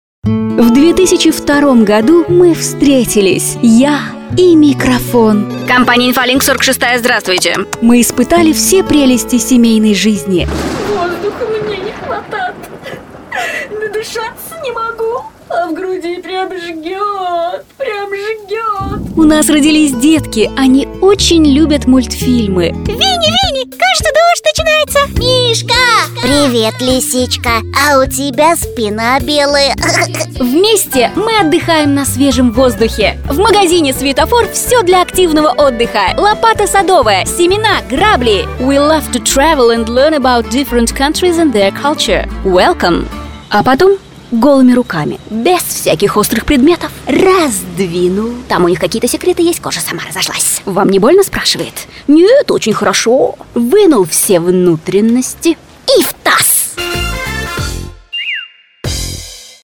Тракт: микрофон Октава МК-219, Звуковая карта Focusrite Scarlett 2i2, звукоизолированная кабина для записи.